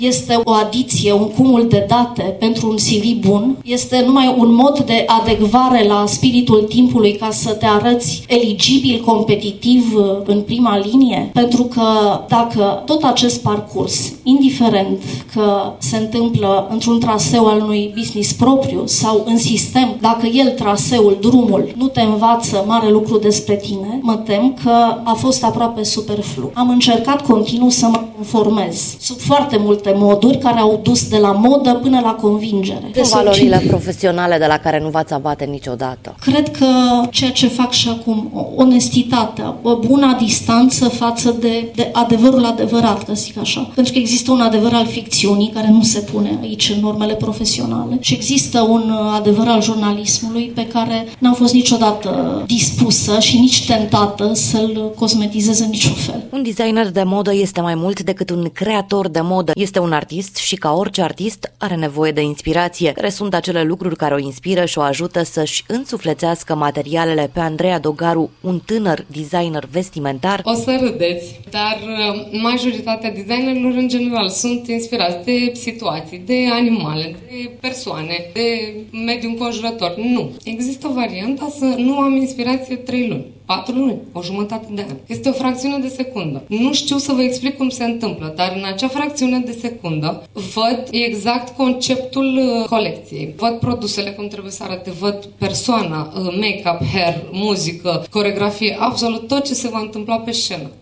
Un reportaj
reportaj-femei-de-cariera-12-mai.mp3